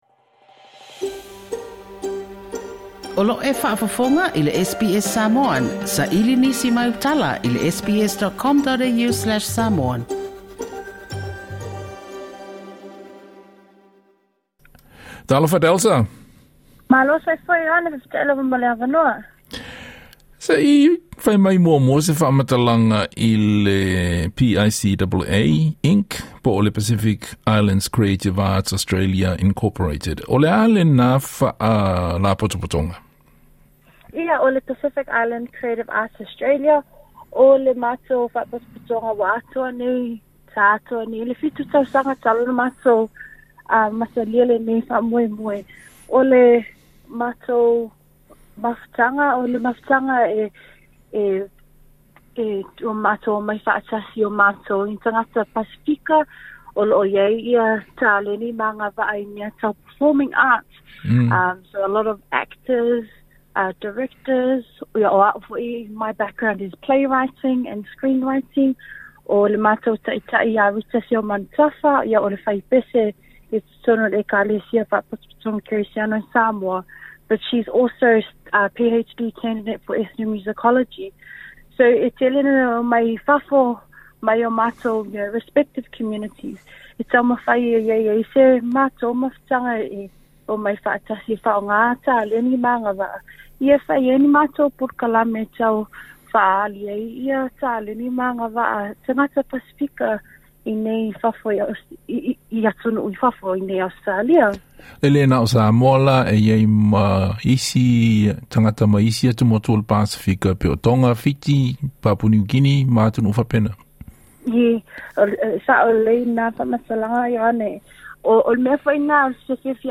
TALANOA